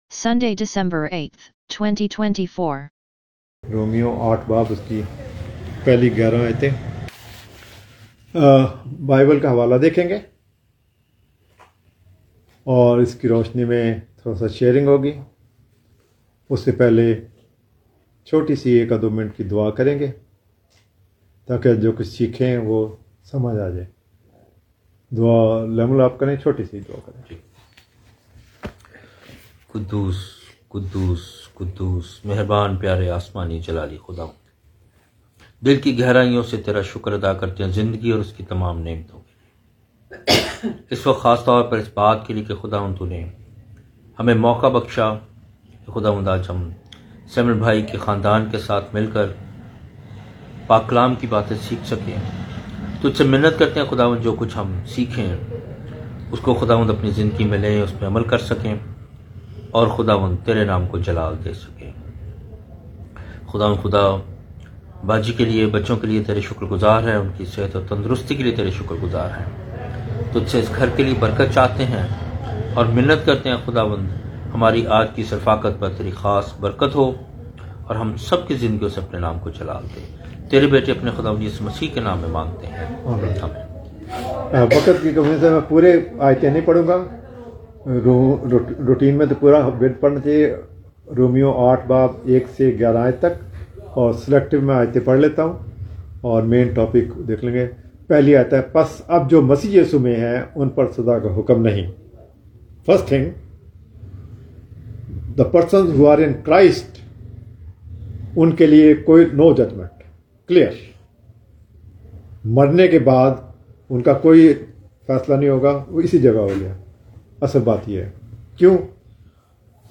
Audio Sermons Idolatry In Christ no condemnation